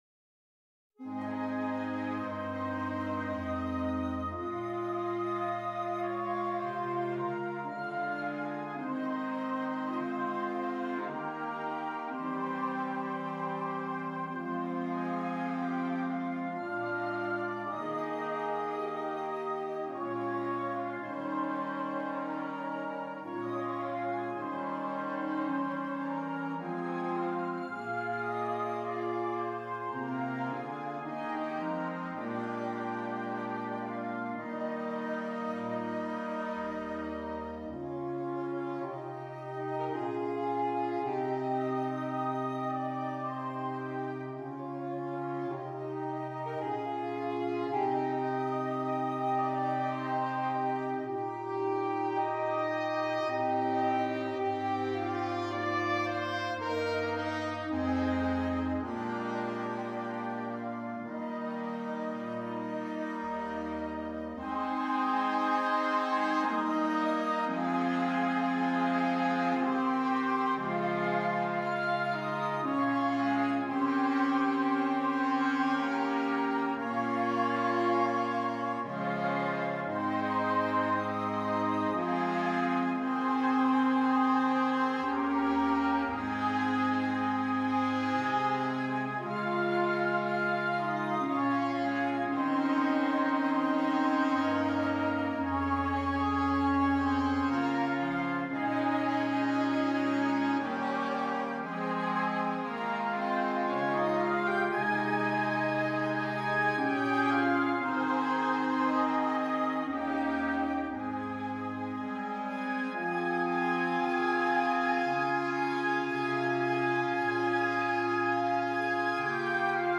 Gattung: für flexibles Holzbläserquintett
Besetzung: Ensemblemusik für 5 Holzbläser